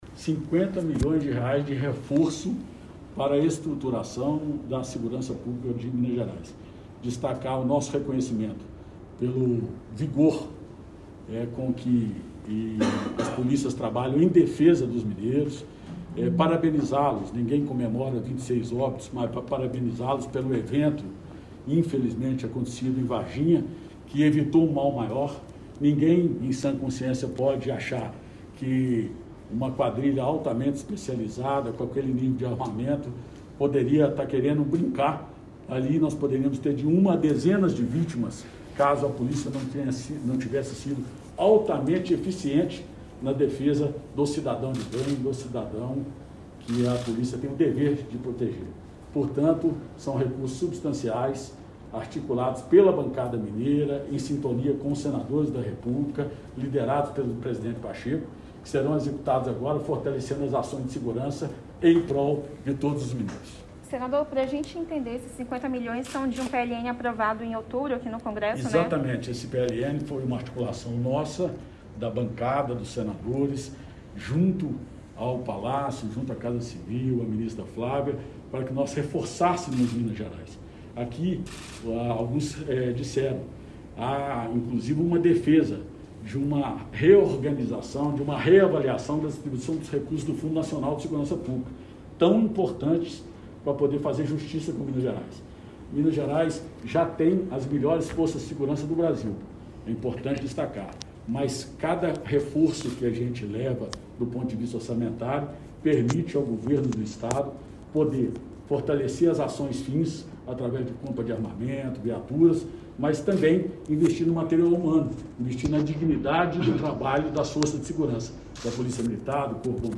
Ouça o pronunciamento de Alexandre Silveira: